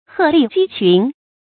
成語注音ㄏㄜˋ ㄌㄧˋ ㄐㄧ ㄑㄩㄣˊ
成語拼音hè lì jī qūn
鶴立雞群發音
成語正音鶴，不能讀作“háo”。